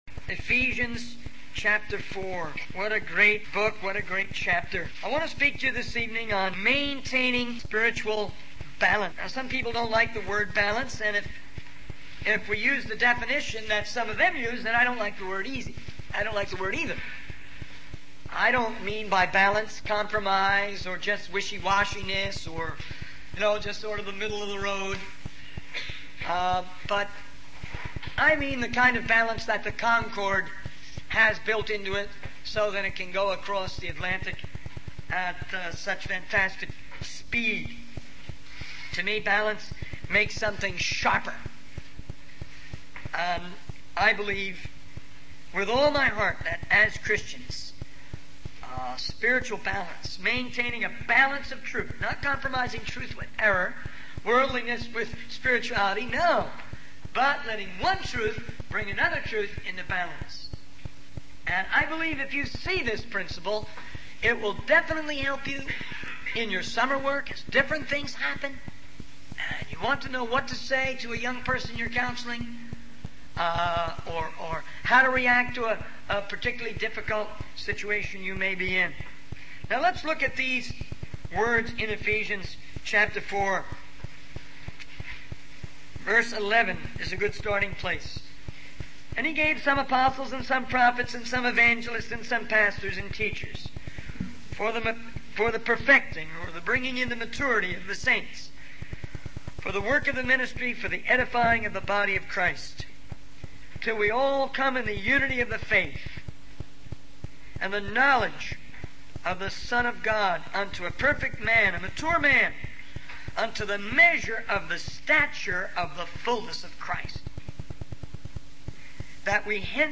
In this sermon, the speaker discusses the importance of balance in the Christian life. He emphasizes the role of different spiritual gifts in the church, such as apostles, prophets, evangelists, pastors, and teachers, in bringing maturity and unity to believers. The speaker also highlights the need for balance between spiritual warfare and rest, drawing from Hebrews chapter four.